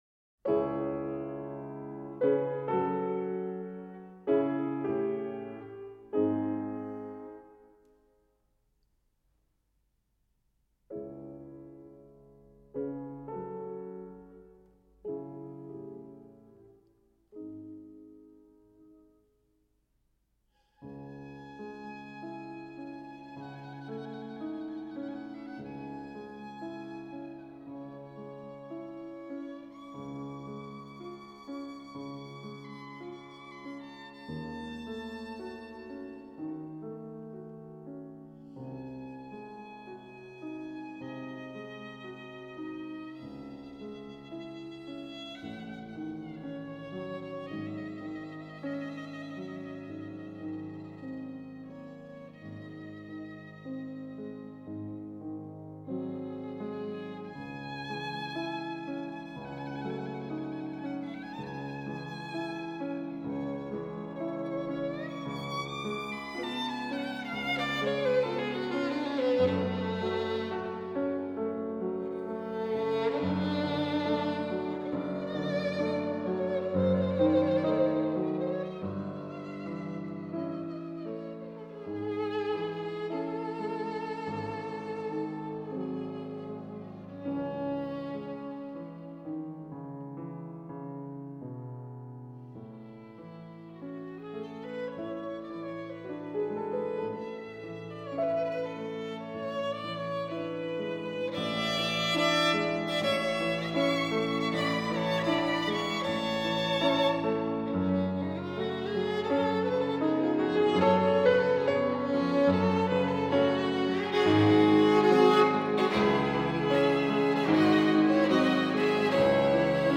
11 Nocturne Op. Posth (arr. Milstein)
11-nocturne-op-posth-arr-milstein.m4a